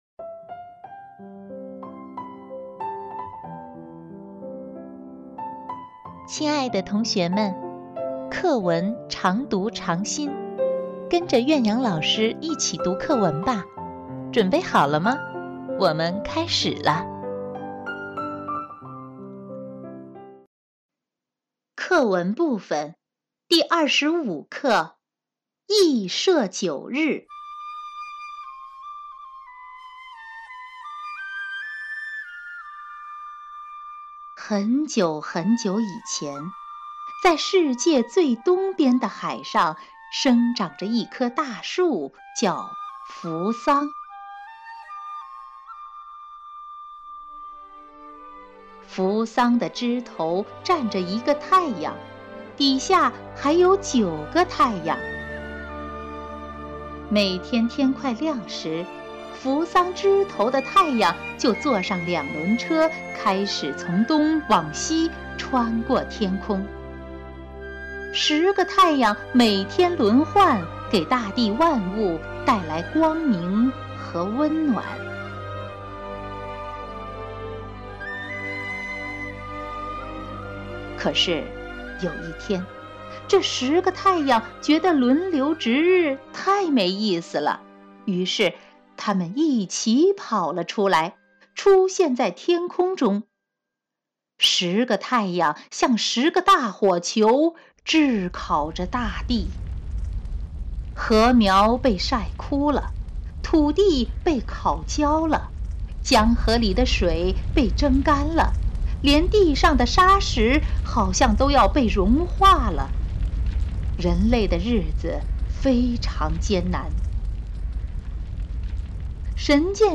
课文朗读